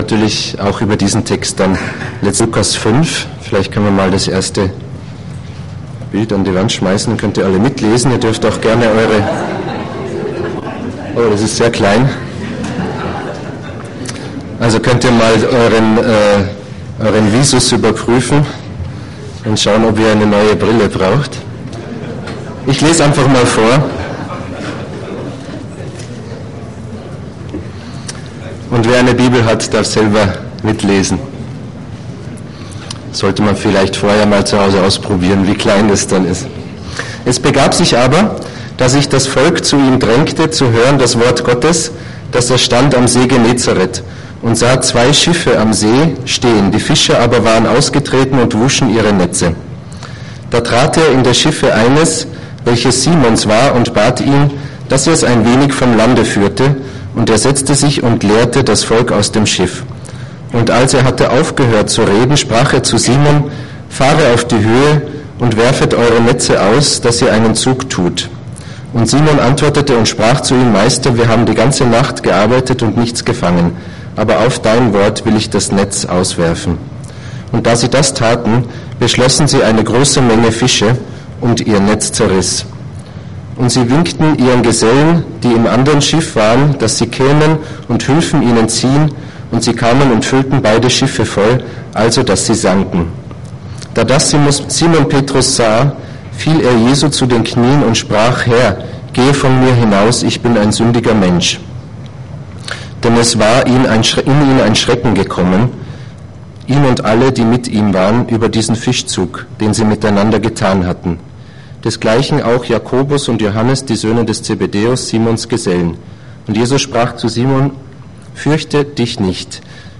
Predigt zu Lukas 5, 1-11